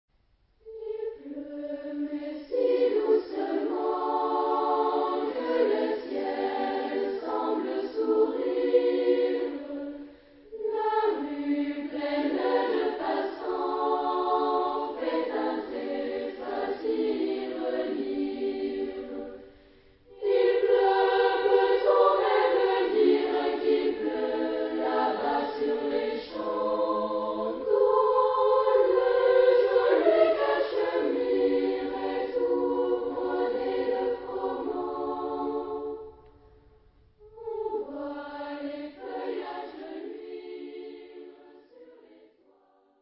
Genre-Style-Forme : Moderne ; Enfants ; Profane
Tonalité : sol majeur